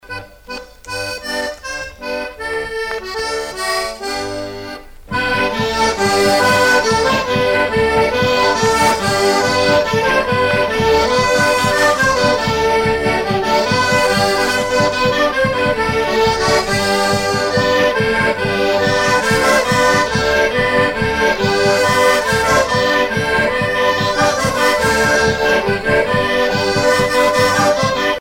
Genre laisse
Festival folklorique de Matha 1980